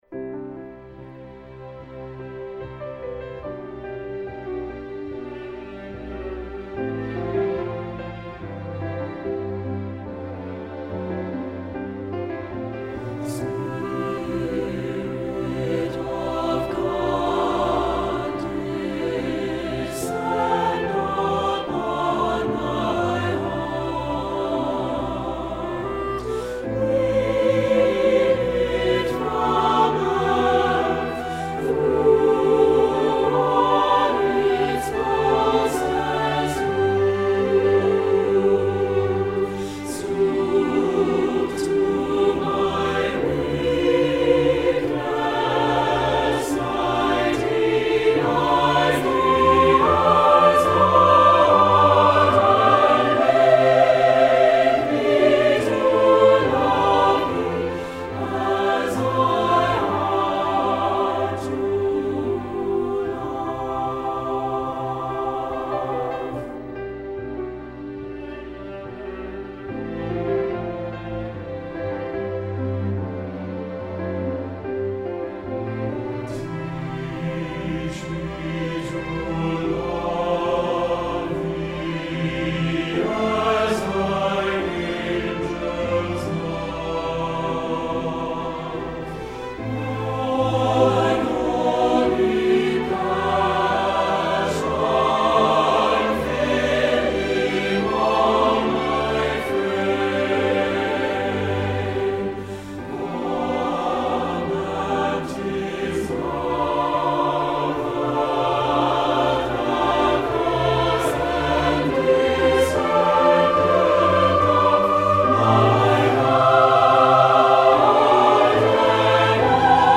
Voicing: Strg Ens,C